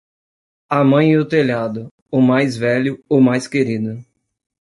Pronúnciase como (IPA)
/teˈʎa.du/